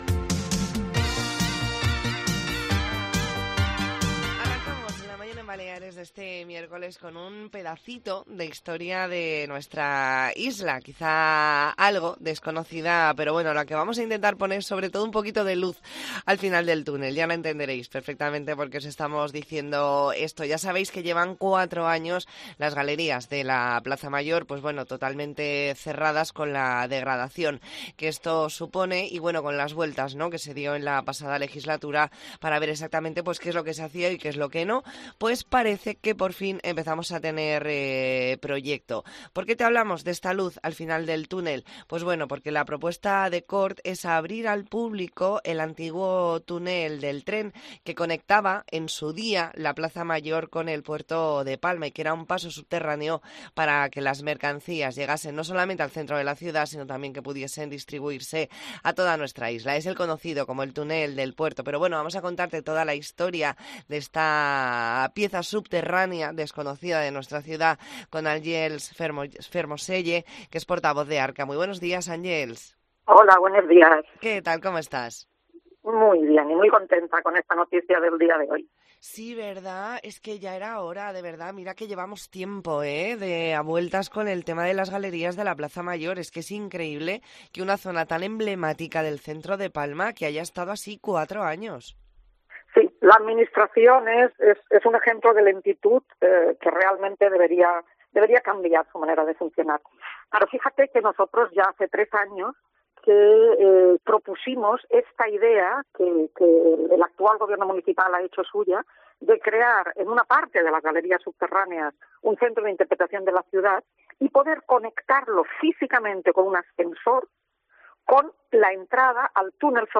Entrevista en La Mañana en COPE Más Mallorca, miércoles 13 de diciembre de 2023.